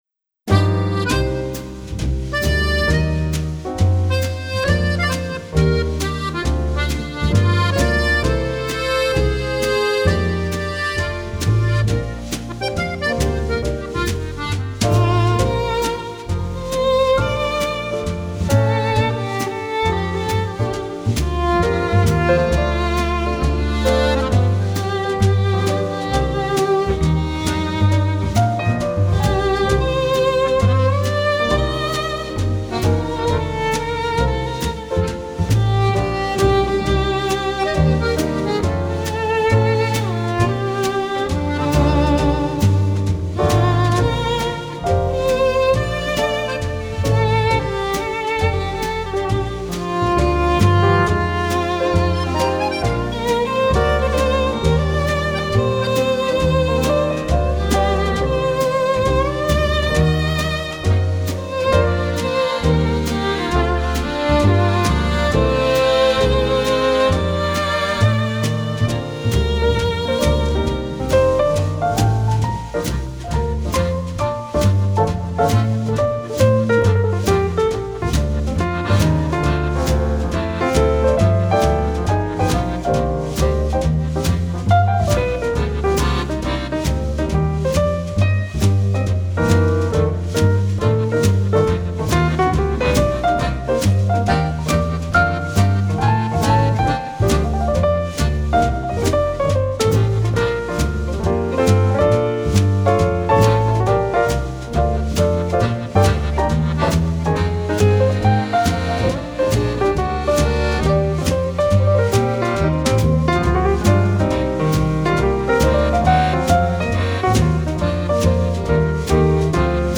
А так как мне почти всегда нравится своеобразное триединство ( клипповость)) - изображение (в данном случае -фото) + соответствующая музыка +  слова (найти самые подходящие слова - чуть ли не самое сложное)),  то и появилась ещё  и музыка - между спокойной оркестровой и джазом - предпочтение отдано джазу - а комментарии показались  ненужными.))